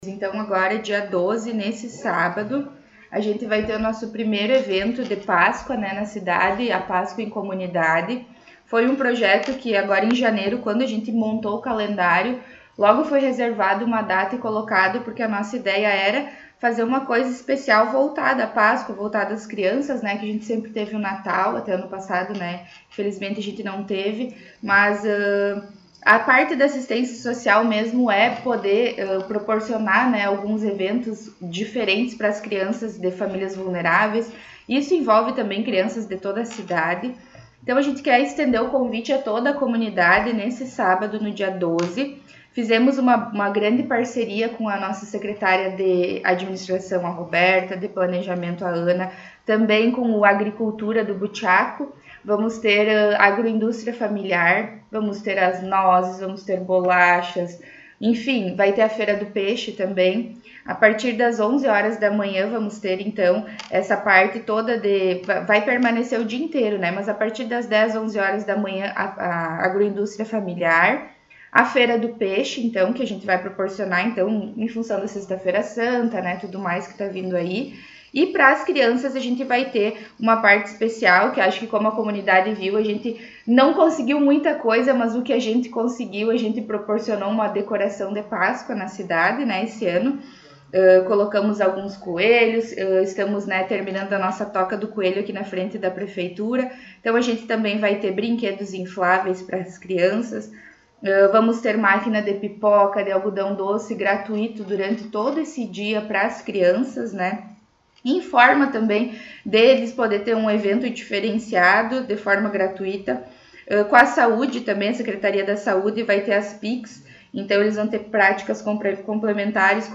Secretária Municipal, Suelen Castro, concedeu entrevista